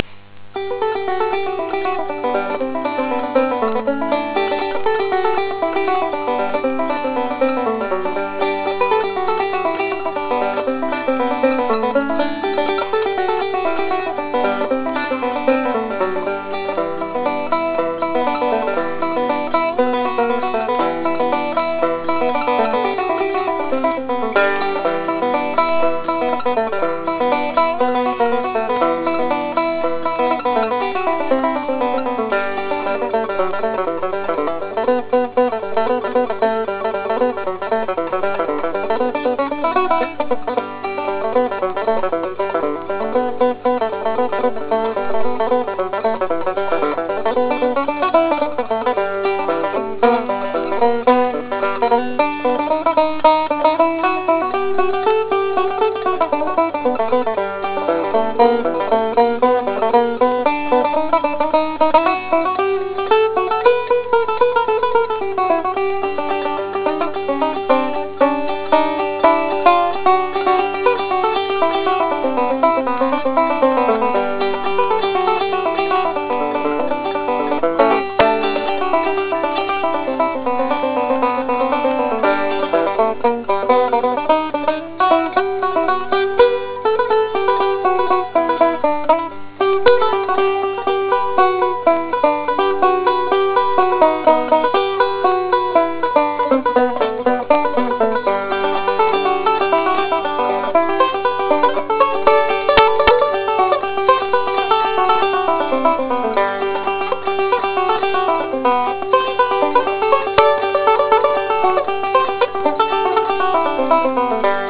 Banjo Out-takes
A slower version of Blackberry Blossom, but still with goofs.